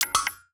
Universal UI SFX / Clicks
UIClick_Double Click Metallic 01.wav